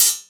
Tuned hi hats Free sound effects and audio clips
• Studio Open High-Hat Sound B Key 13.wav
Royality free open hat sample tuned to the B note. Loudest frequency: 8126Hz
studio-open-high-hat-sound-b-key-13-MBh.wav